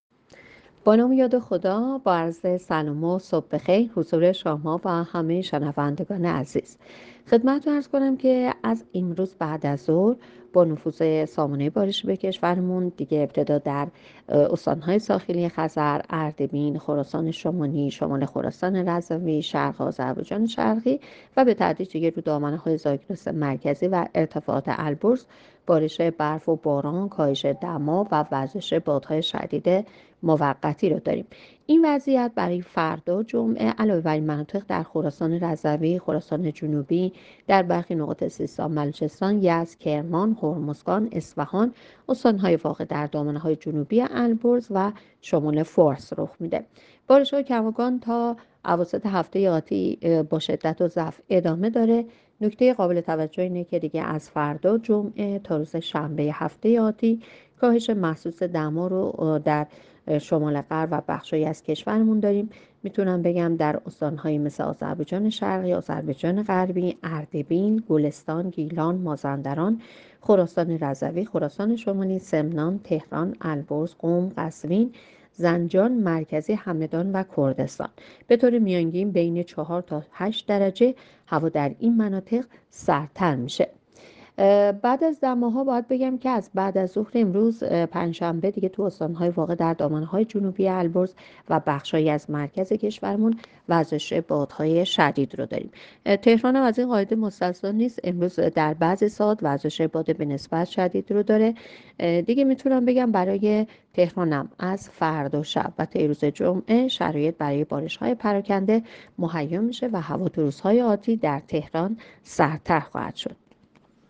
گزارش رادیو اینترنتی پایگاه‌ خبری از آخرین وضعیت آب‌وهوای ۲۷ دی؛